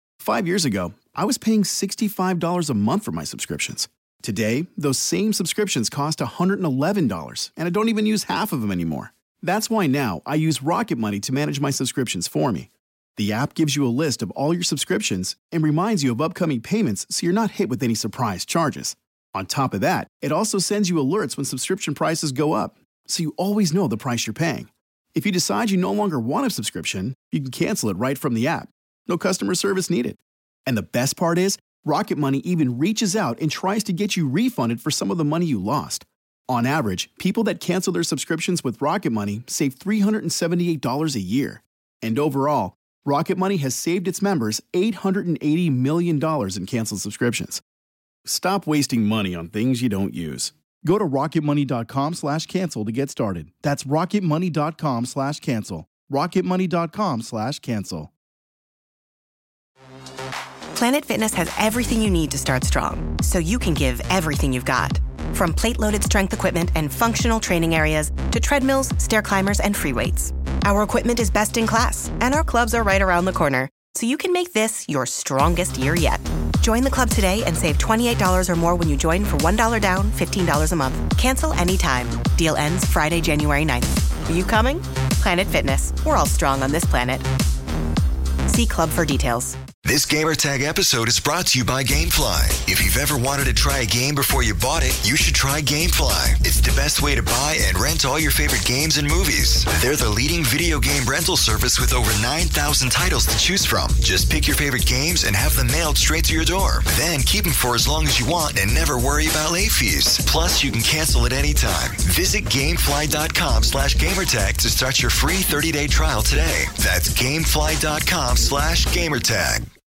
E3 2016 day 1 roundtable discussion with special guests Videogame BANG.